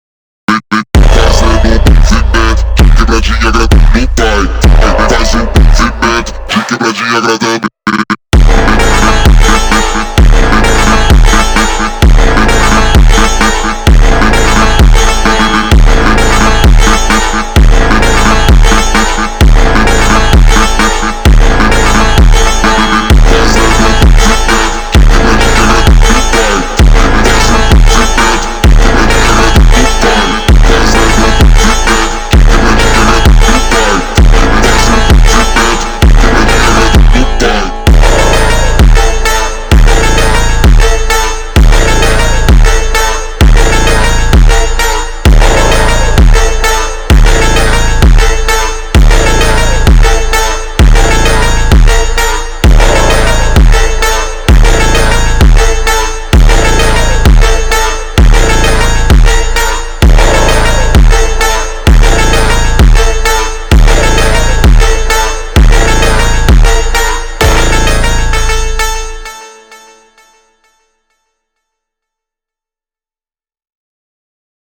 سبک ریمیکس